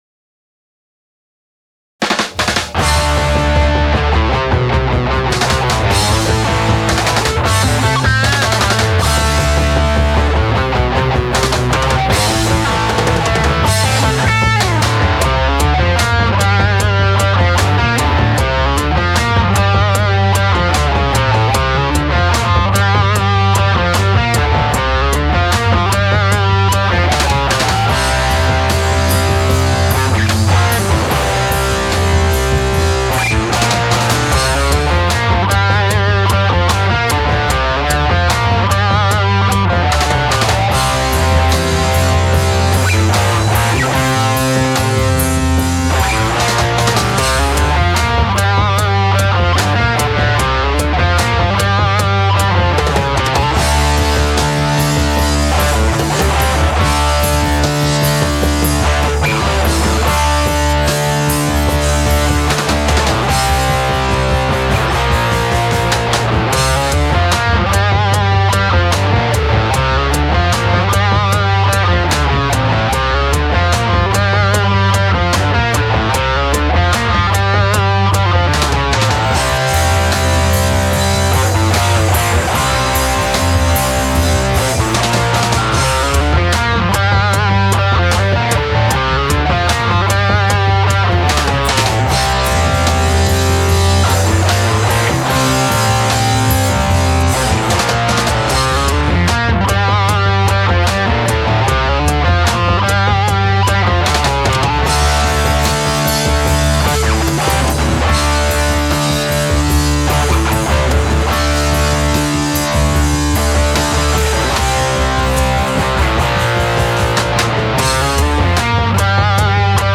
The Mojo pickups sound good to me
Here's a quick sound demo of the LP Jr
Here's the LP Jr in action